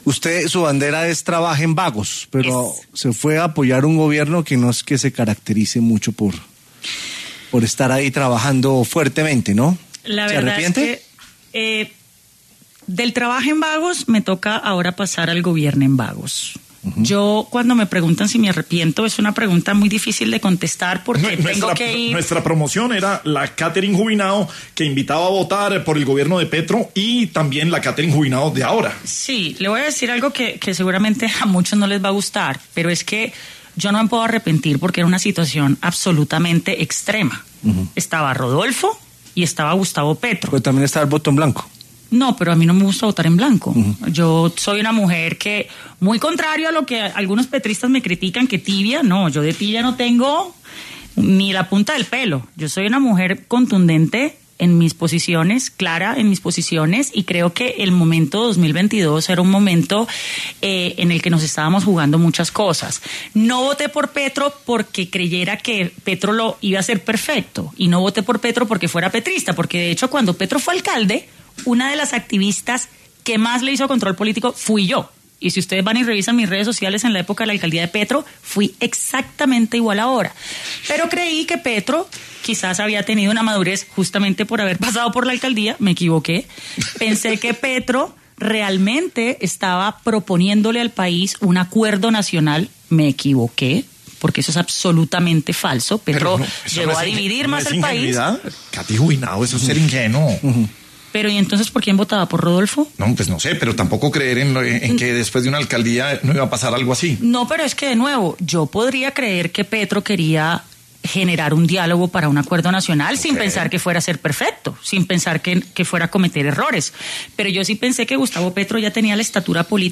La representante a la Cámara, Catherine Juvinao, estuvo en los micrófonos de La Luciérnaga, en Sin Anestesia y abordó el apoyo que le dio a Gustavo Petro en las presidenciales de 2022.